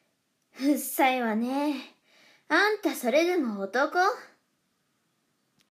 サンプルボイス やんちゃ 【少女】